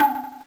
HeavenStudio/Assets/Resources/Sfx/games/tramAndPauline/transformTram.ogg at 3125d41f8bd6169ebad556e853e94511ace4e6d3
transformTram.ogg